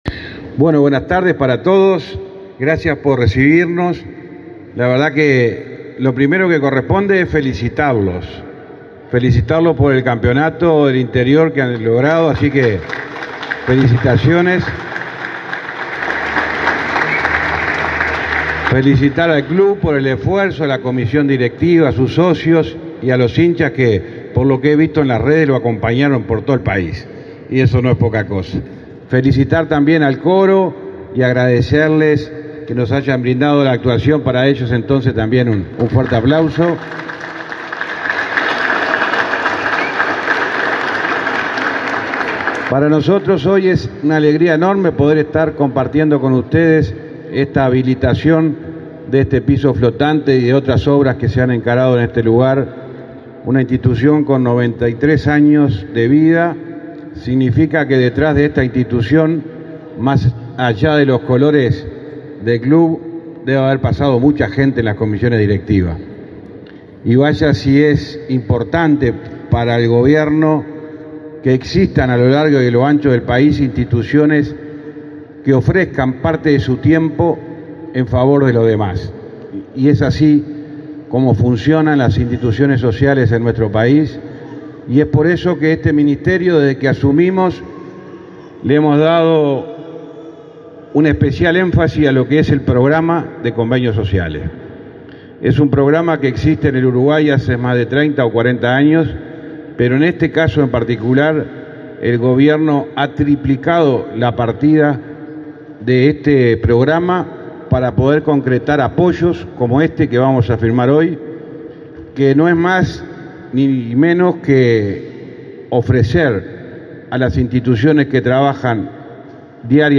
Palabras del ministro del MTOP, José Luis Falero
Palabras del ministro del MTOP, José Luis Falero 04/09/2023 Compartir Facebook X Copiar enlace WhatsApp LinkedIn El Ministerio de Transporte y Obras Públicas (MTOP) inauguró, este 4 de setiembre, el piso flotante del Club Universitario de Salto. El ministro José Luis Falero participó del evento.